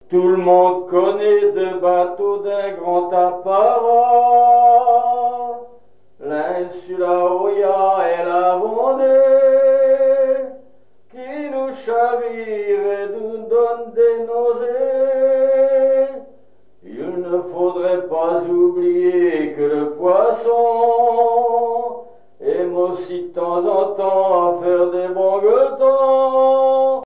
Genre strophique
Catégorie Pièce musicale inédite